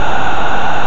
Ou seja, o som do sinal resultante deverá se parecer com a vogal emitida no sinal.
brancoFiltA.wav